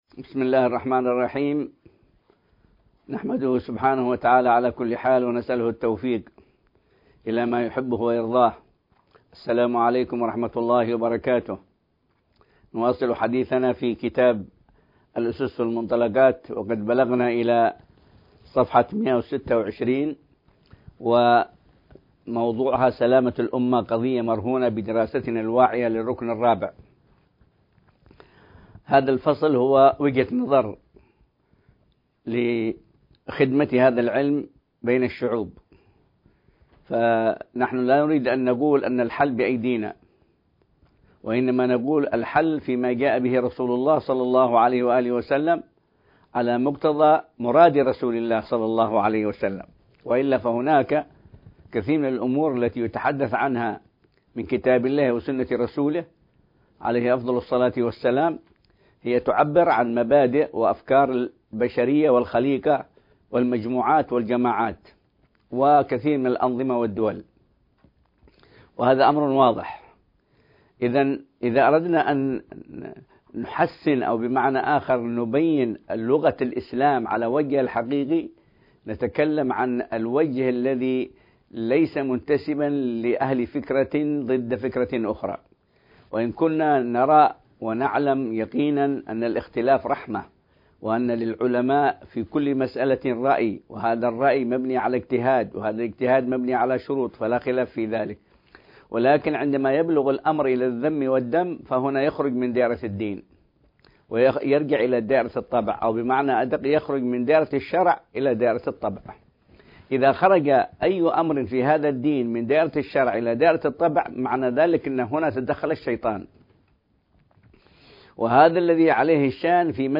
شرح كتاب الأسس والمنطلقات | المحاضرة الراربعة عشر